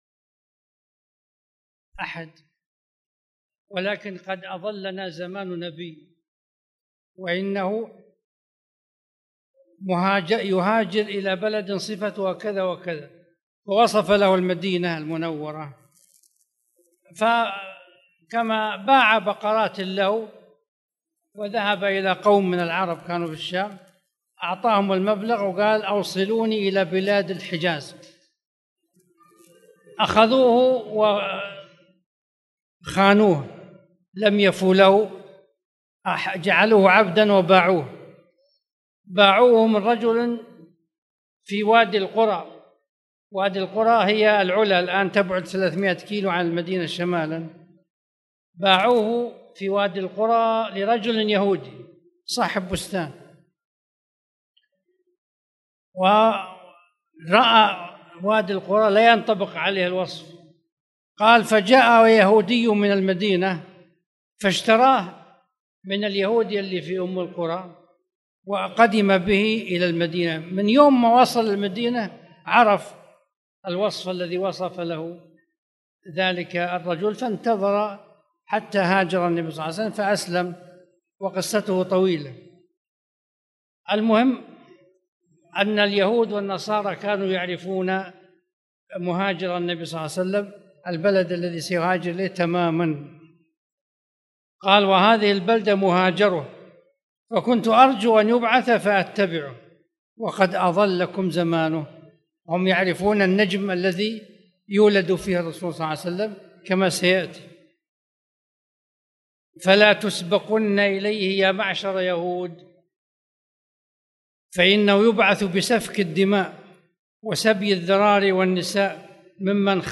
تاريخ النشر ٩ ربيع الثاني ١٤٣٨ هـ المكان: المسجد الحرام الشيخ